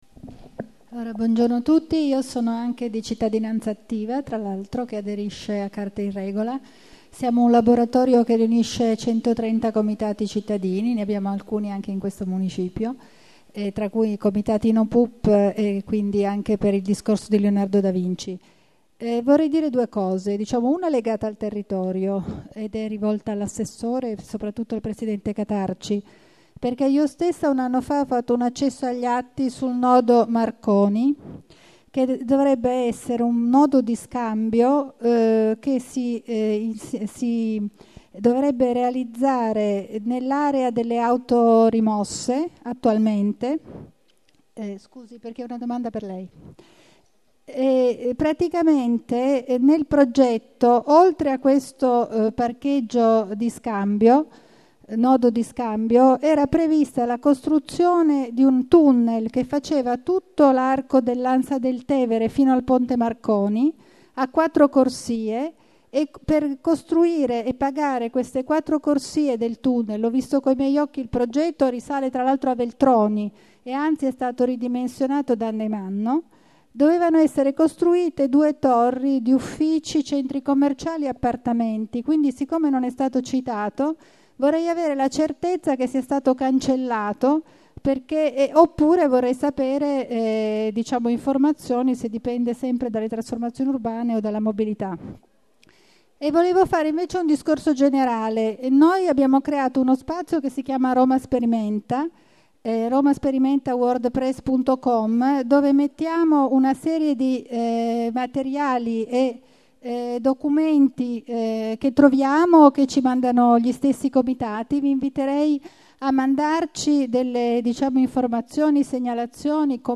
Registrazione integrale dell'incontro svoltosi il 7 luglio 2014 presso l'Urban Center di Via Niccolò Odero